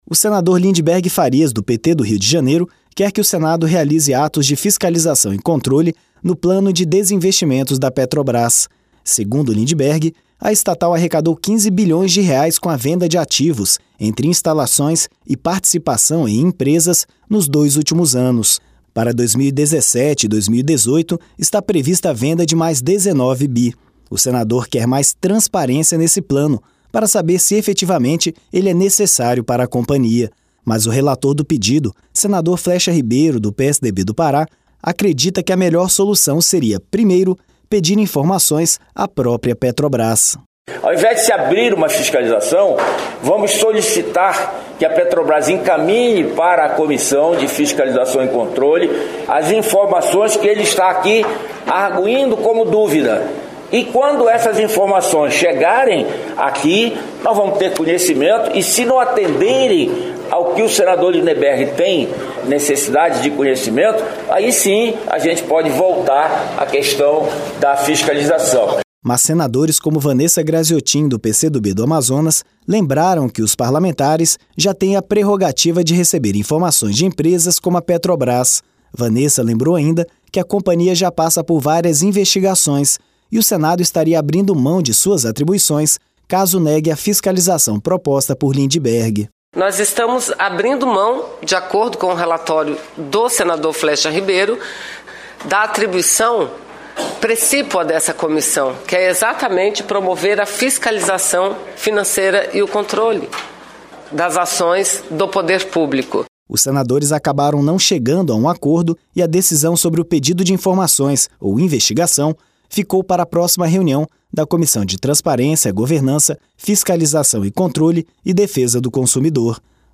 Ouça os detalhes no áudio do repórter da Rádio Senado